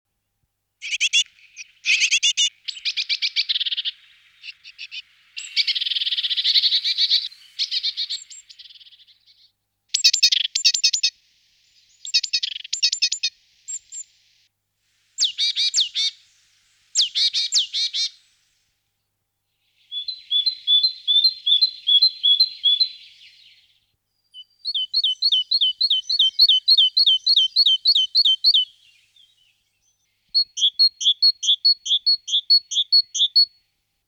Cinciallegra – Orto botanico
cinciallegra.mp3